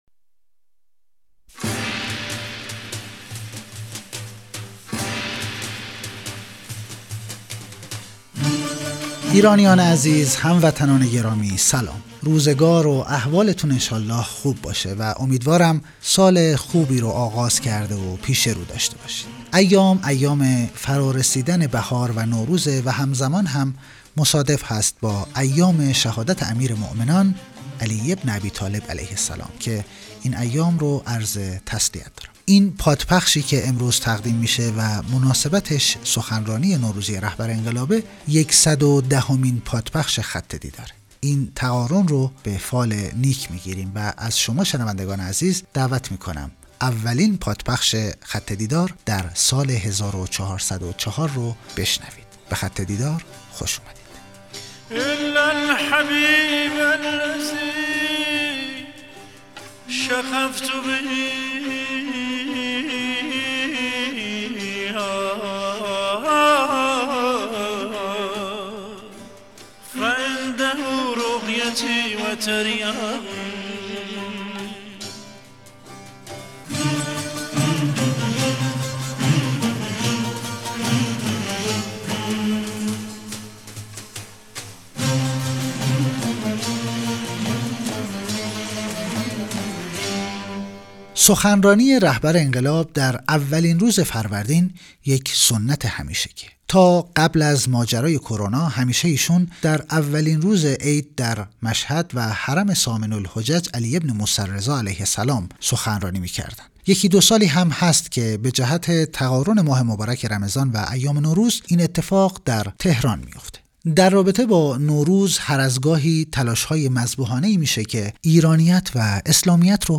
سخنرانی نوروزی در اجتماع اقشار مختلف مردم تهران
بیانات در دیدار نوروزی با هزاران نفر از قشرهای مختلف مردم